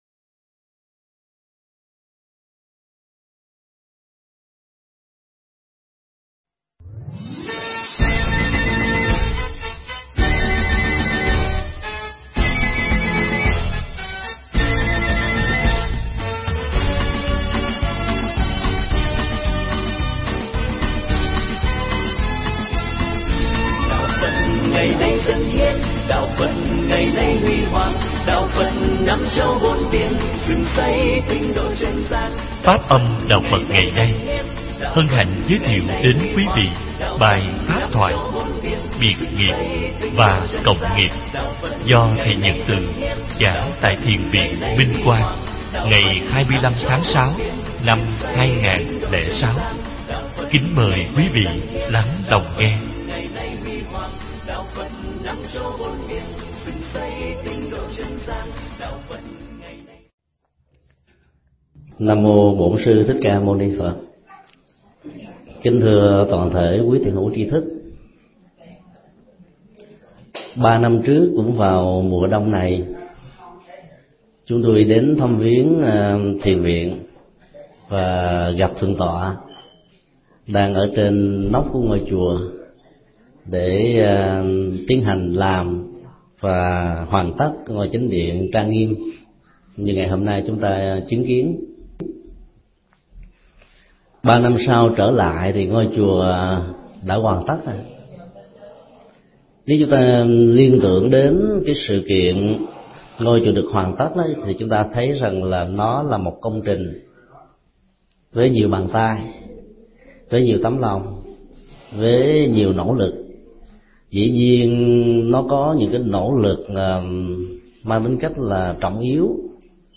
Mp3 Pháp thoại Biệt Nghiệp Và Cộng Nghiệp
tại Thiền Viện Minh Quang – Australia